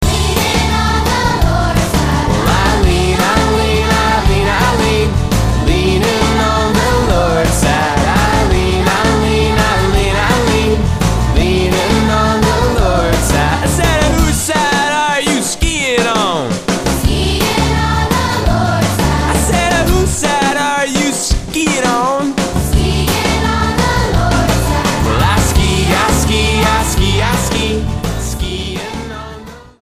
STYLE: Childrens
Certainly the musicianship is of a high standard